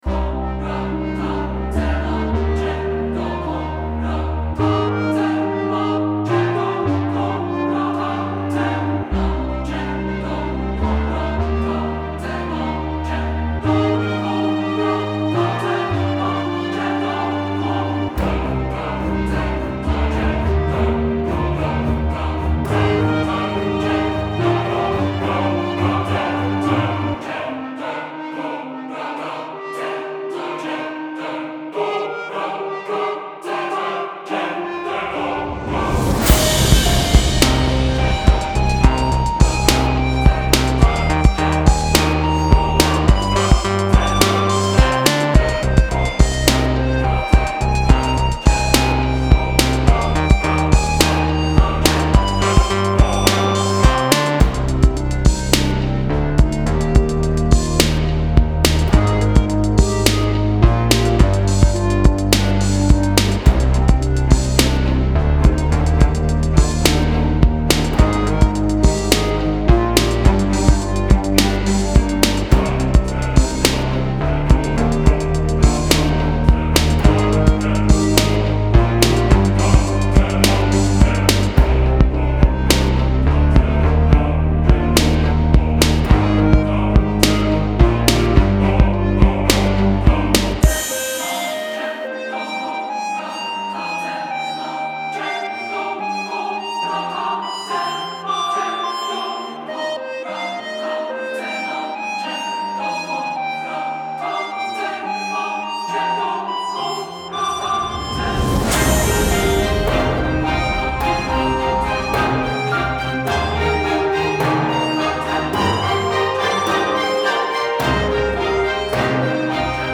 Style Style Orchestral, Soundtrack
Mood Mood Driving, Epic, Intense
Featured Featured Bass, Cello, Choir +2 more
BPM BPM 106